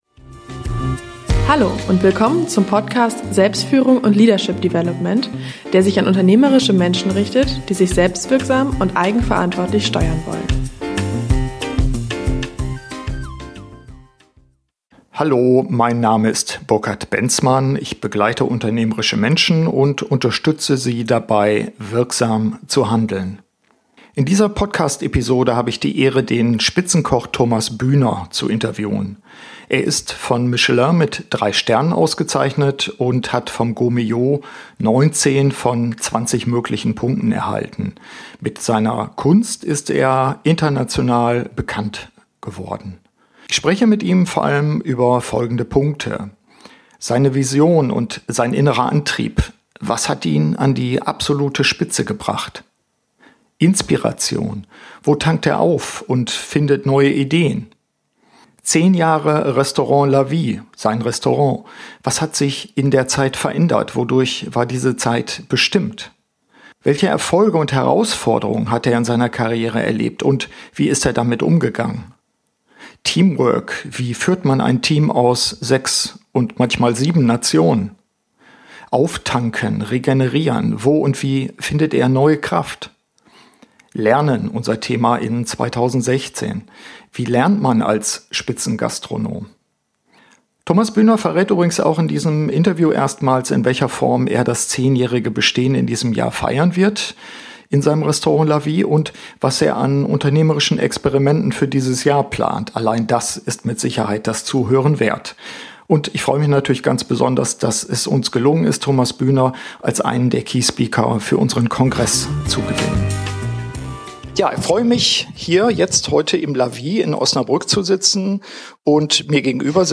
Interview mit dem 3-Sterne-Koch Thomas Bühner vom Restaurant La Vie: Seine Vision und sein innerer Antrieb: Was hat ihn an absolute Spitze gebracht?